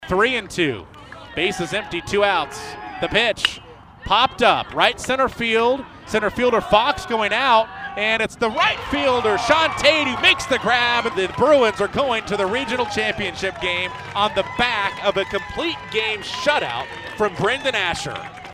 Here is out the final out sounded on KPGM.